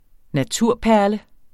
Udtale [ naˈtuɐ̯pæɐ̯lə ]